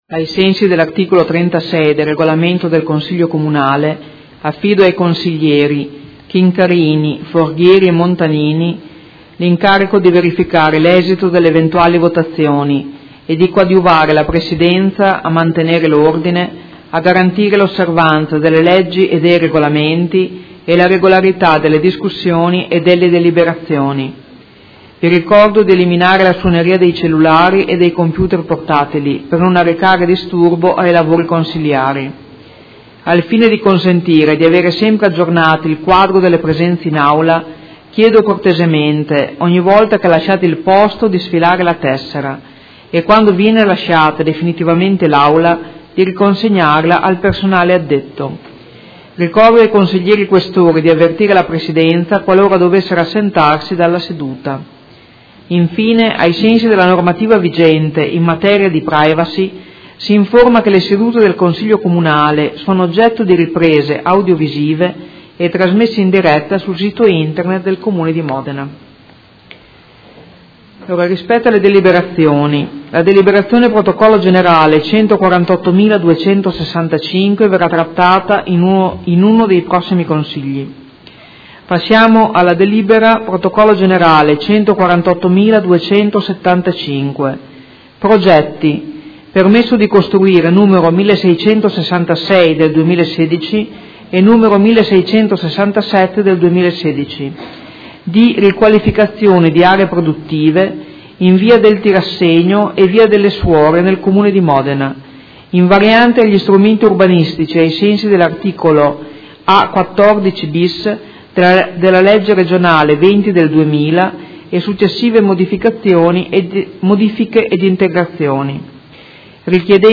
Seduta del 27/10/2016 Apre i lavori del Consiglio.
Presidentessa